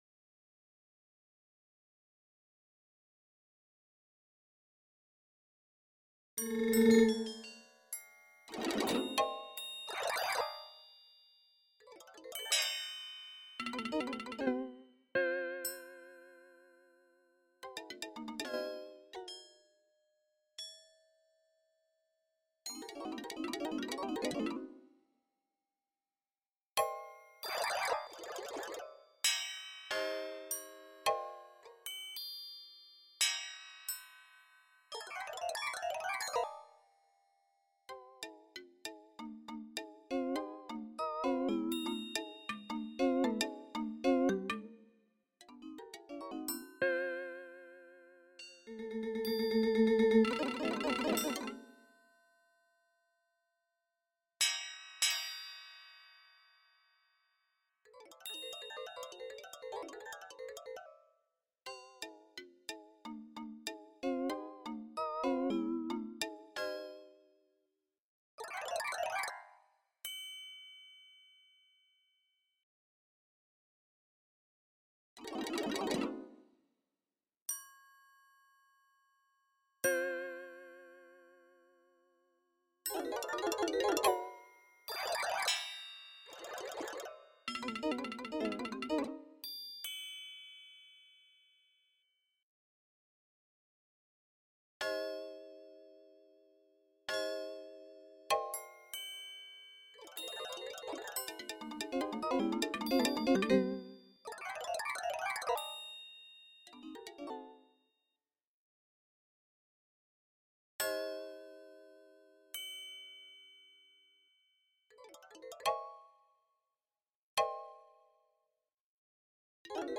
Two assisted performances
In both these assisted recordings, the Performance Options were set so that the Assistant Performer performed the top staff, while staves 2 and 3 were performed (conducted) live.
1. the assistant’s speed is relative to the live performer’s most recently performed durations.
The minimum ornament chord duration was set to 1 millisecond.